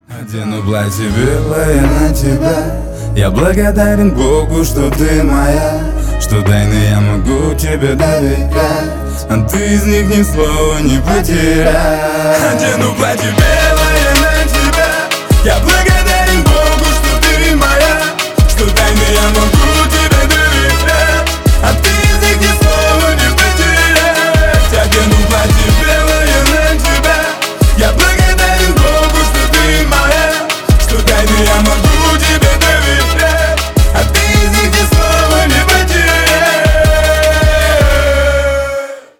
рэп , хип-хоп